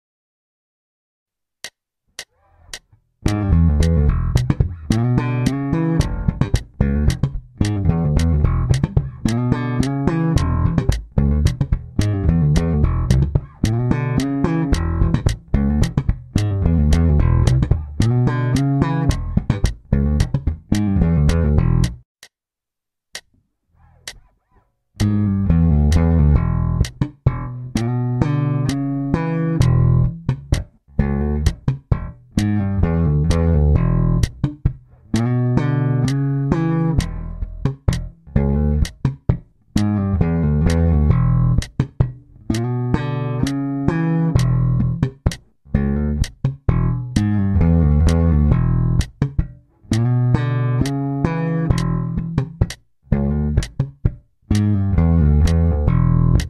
L05 Oldschool slap bass with slides
A mean slapline with pulloffs and slided doublestop chords.
Can be played over an E7 or Em 7 chord
L05E7slapbasslinewithslides.mp3